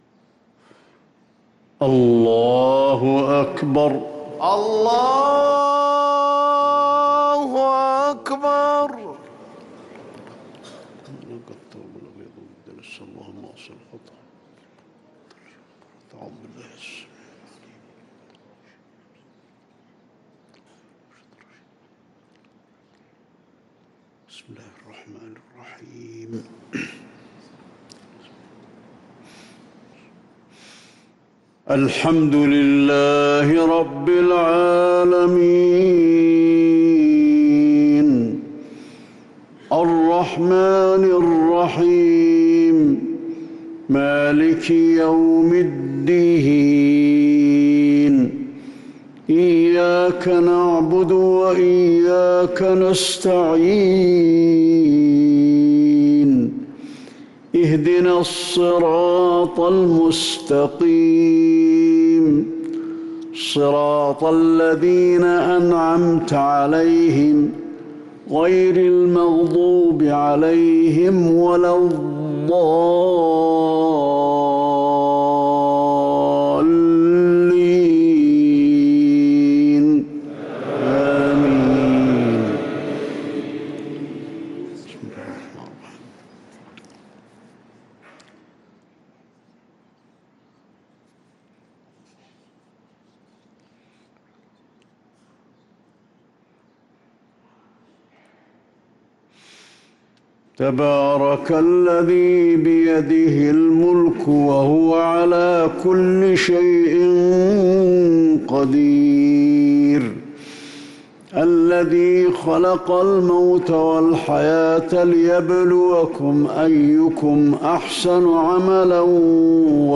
صلاة الفجر للقارئ علي الحذيفي 6 ذو الحجة 1444 هـ
تِلَاوَات الْحَرَمَيْن .